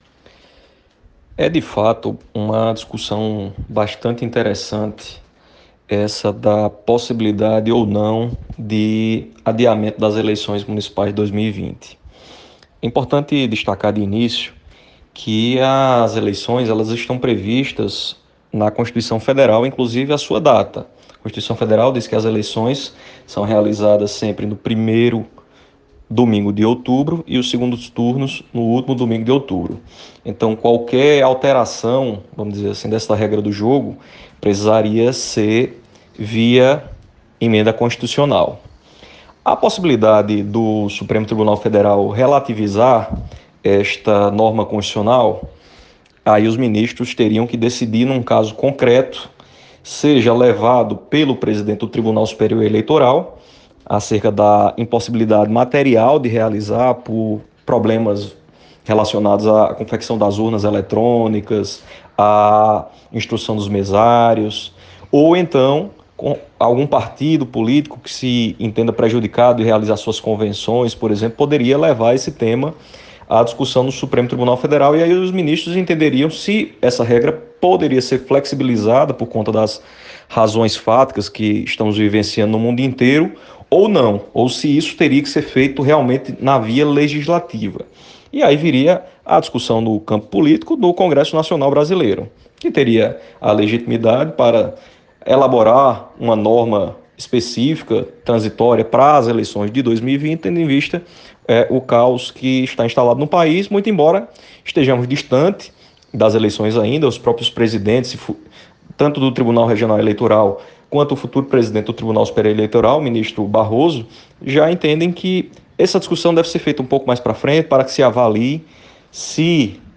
Em entrevista ao blog